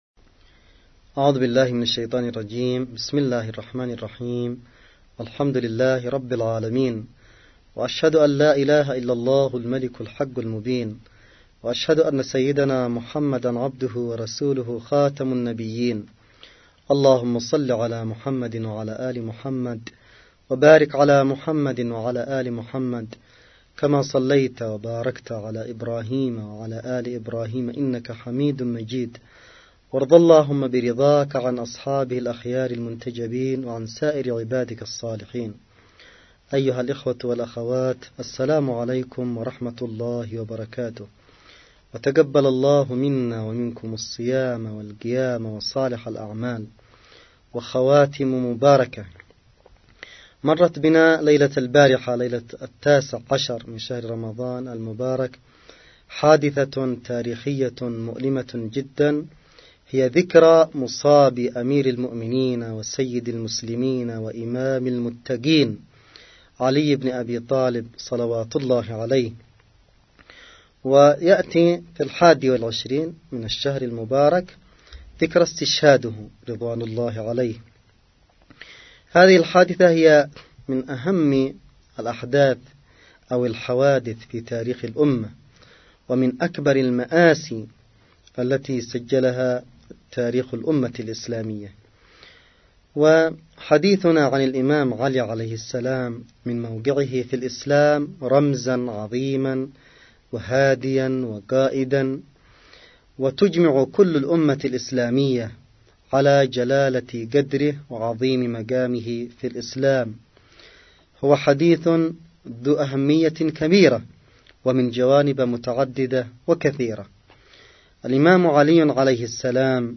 نص+ أستماع للمحاضرة الرمضانية 12 للسيد الحوثي في ذكرى أستشهاد الأمام علي عليه السلام
محاضرة_رمضانية_للسيد_عبدالملك_بدر.mp3